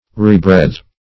Rebreathe \Re*breathe"\ (r[=e]*br[=e]th"), v. t. To breathe again.